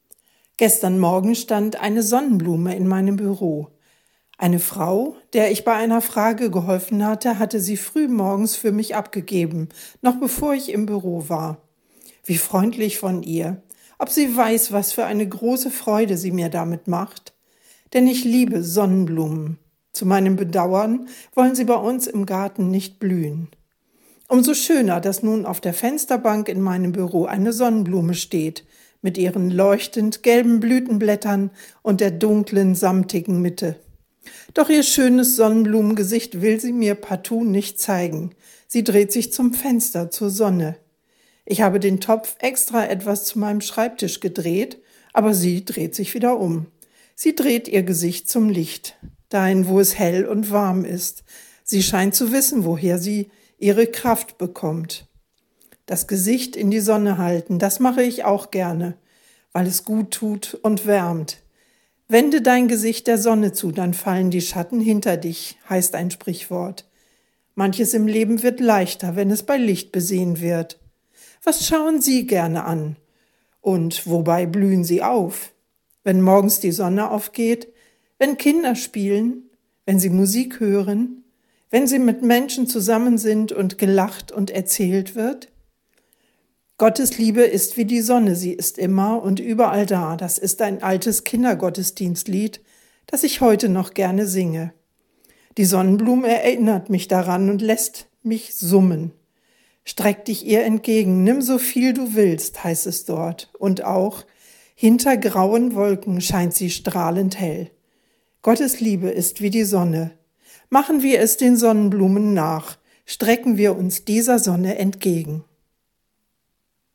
Radioandacht vom 11. Juni